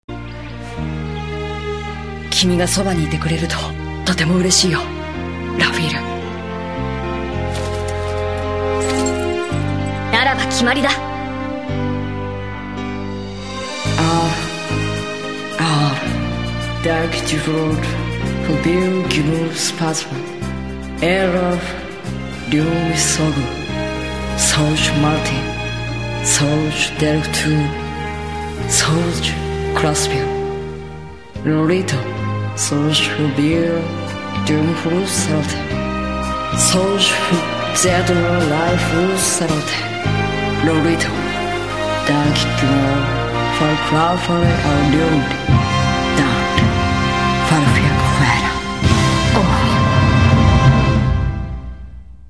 진트의 독백
참고로 배경으로 깔리는 음악은 성계의 문장에서부터 소개되었던 "별들의 권족" 이라는 곡의 후반부입니다.
진트의 낭독에서는 [lyni] 라고 읽는 것처럼 들린다. soglesogh의 보격으로써, 이 문장에서 생략된 동사 ane(이다) 의 보어로 쓰였다.